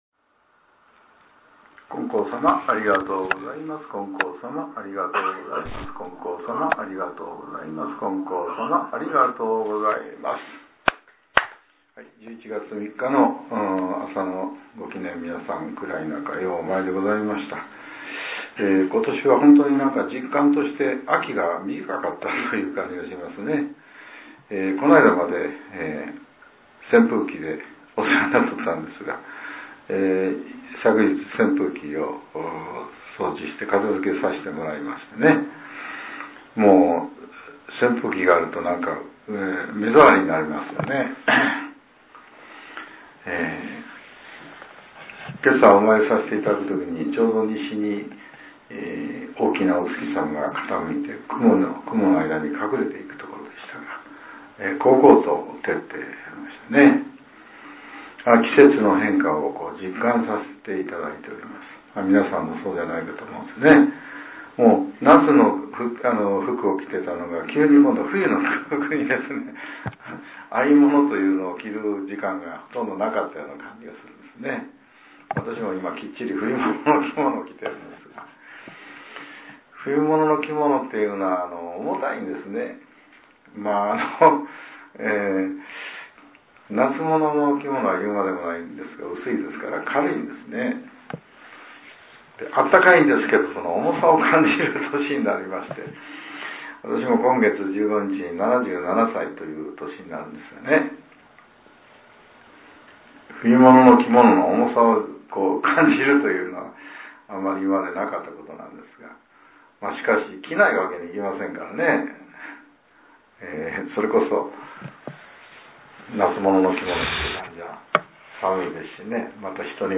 令和７年１１月３日（朝）のお話が、音声ブログとして更新させれています。